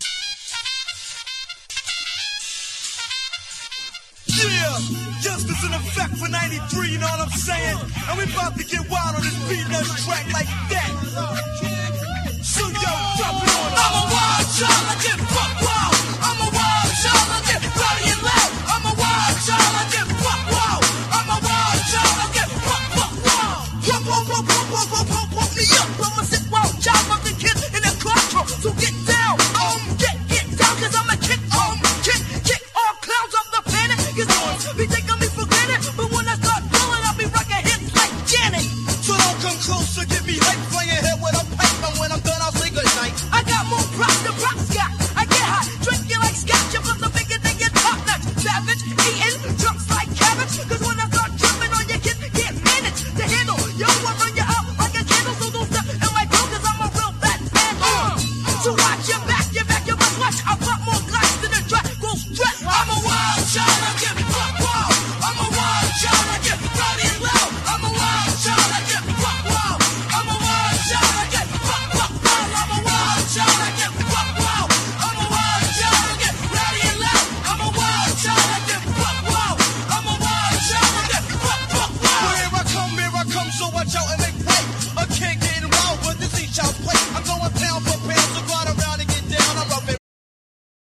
1. HIP HOP >
# 90’S HIPHOP